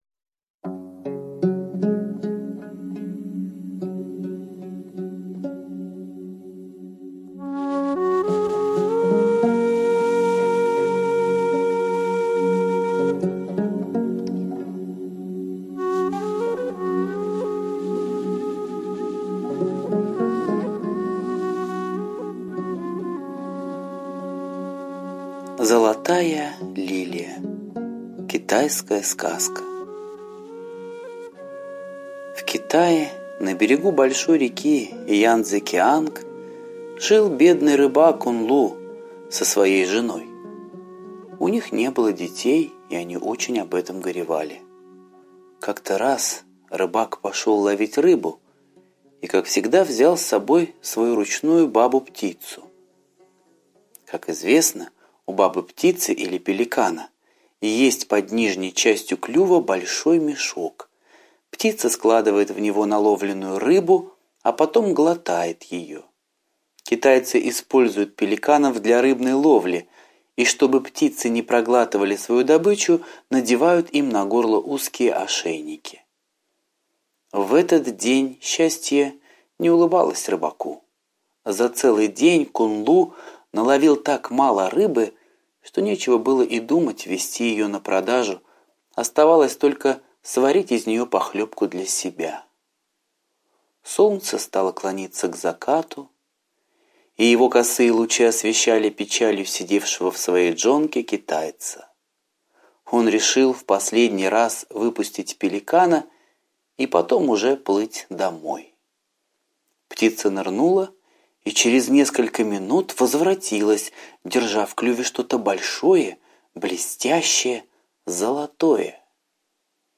Золотая лилия - восточная аудиосказка - слушать онлайн